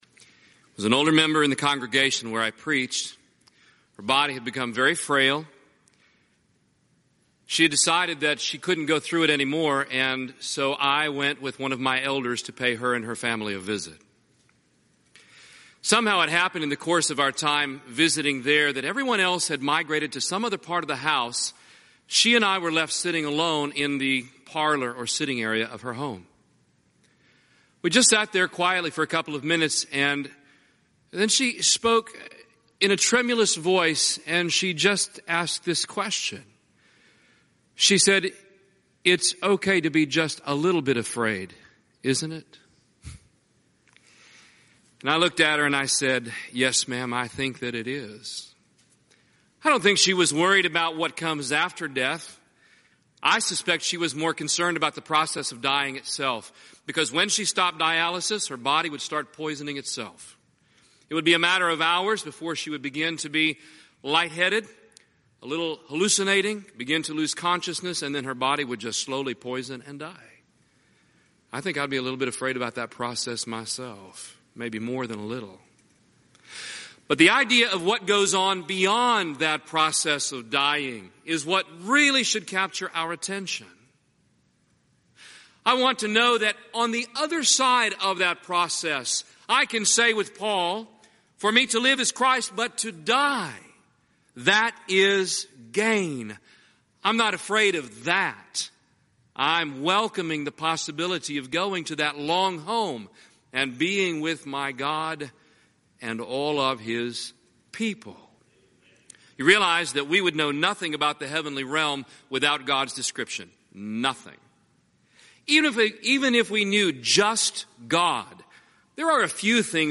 Event: 31st Annual Southwest Lectures
If you would like to order audio or video copies of this lecture, please contact our office and reference asset: 2012Southwest09